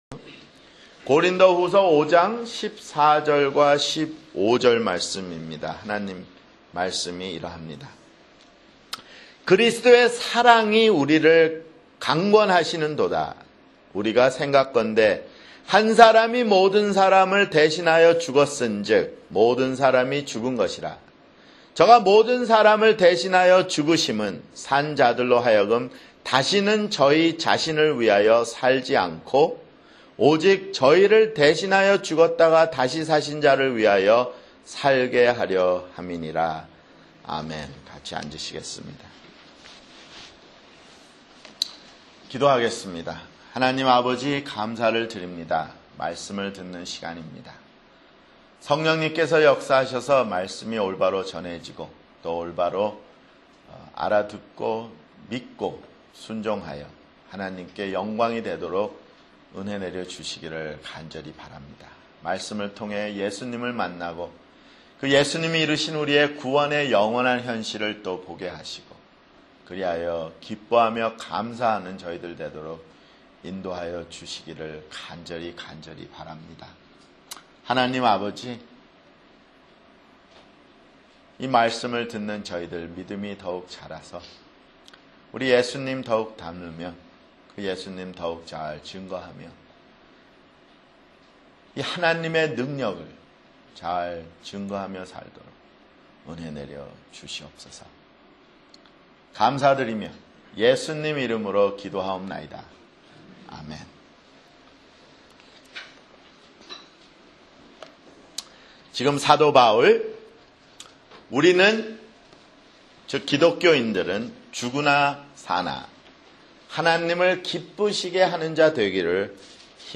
[주일설교] 고린도후서 (29)